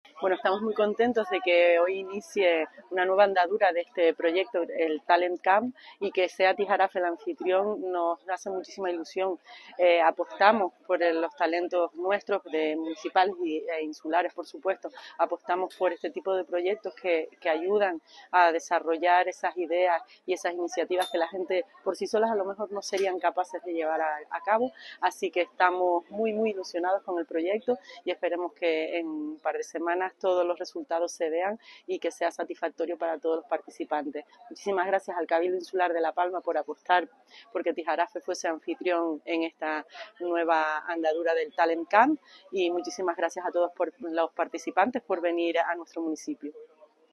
Declaraciones de Yaiza Cáceres.mp3